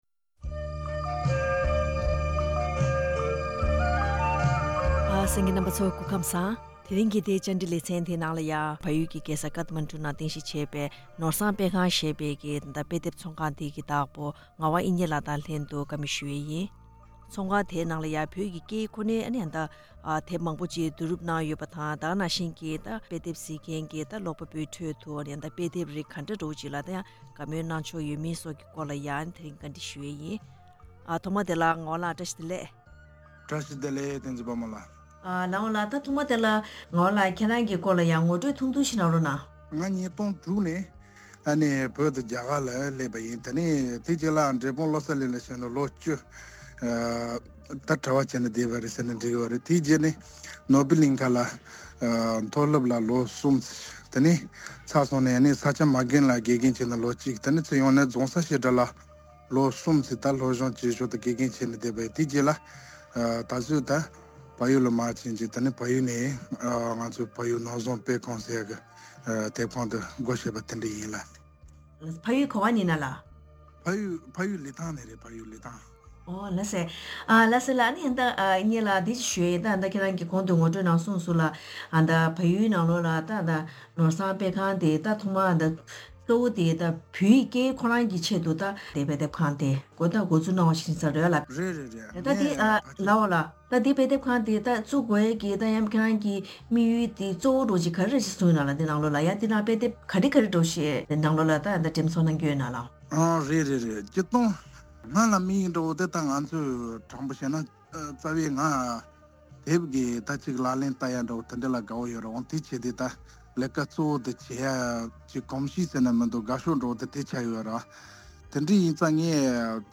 བཀའ་འདྲི་ཞུས་པ་ཞིག་གསན་གནང་གི་རེད།